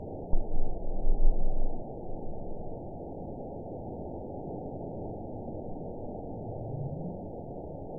event 922517 date 01/14/25 time 15:12:45 GMT (3 months, 2 weeks ago) score 9.23 location TSS-AB06 detected by nrw target species NRW annotations +NRW Spectrogram: Frequency (kHz) vs. Time (s) audio not available .wav